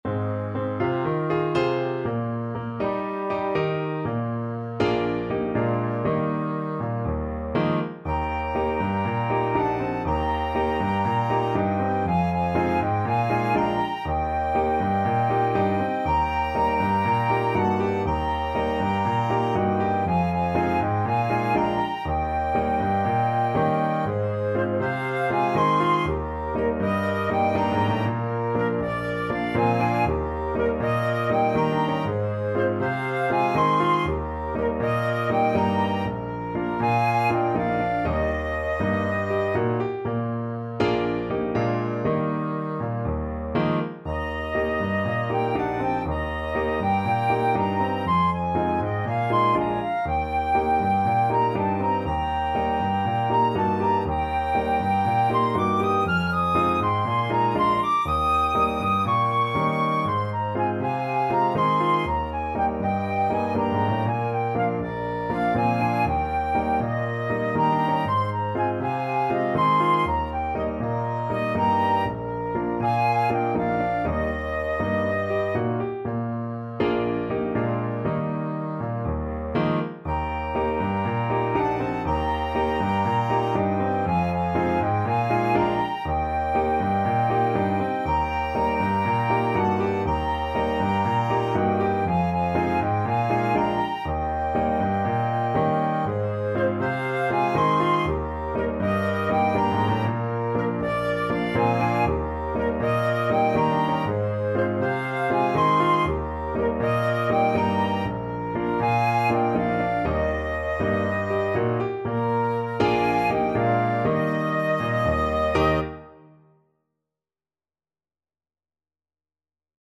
Free Sheet music for Flexible Ensemble and Piano - 3 Players and Piano
Clarinet
French HornAlto Saxophone
Piano
Eb major (Sounding Pitch) (View more Eb major Music for Flexible Ensemble and Piano - 3 Players and Piano )
4/4 (View more 4/4 Music)
Calypso = 120
Traditional (View more Traditional Flexible Ensemble and Piano - 3 Players and Piano Music)